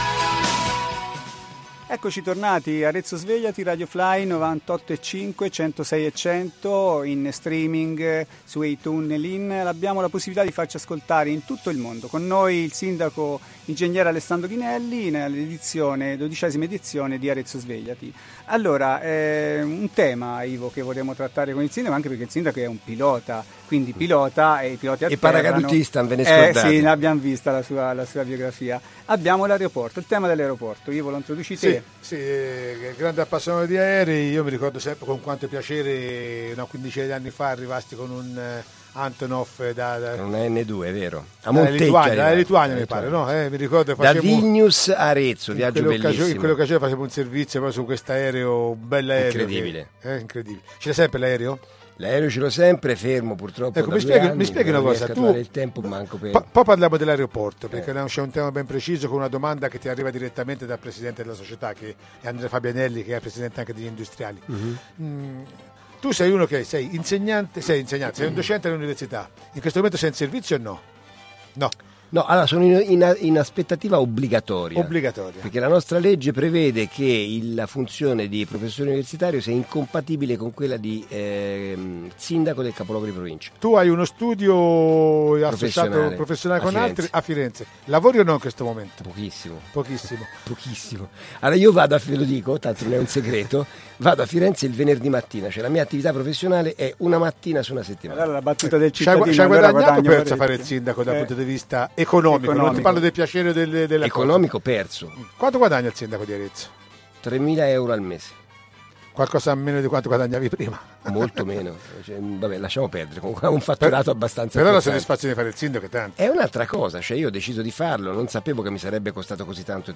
“Arezzo Svegliati” 13° Puntata Parla il Sindaco di Arezzo Ing.Alessandro Ghinelli – RadioFly